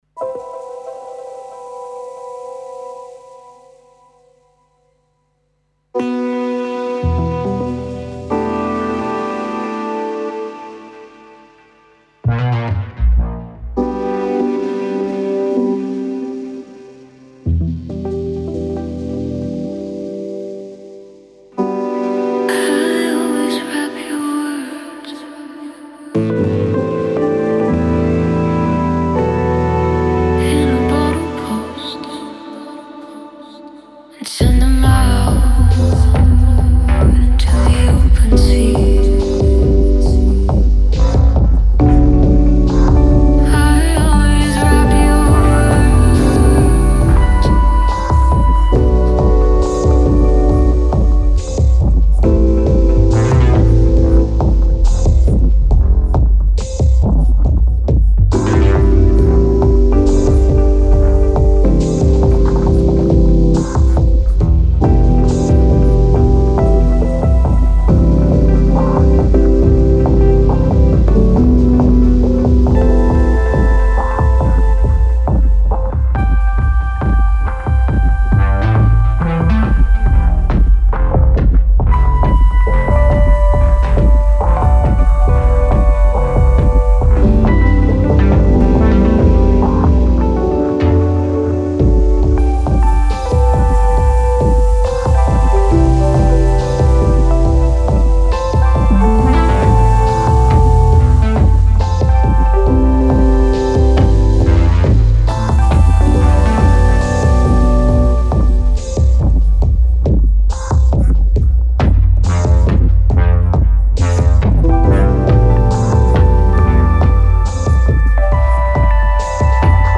Vocals: überarbeitete KI-Stimme.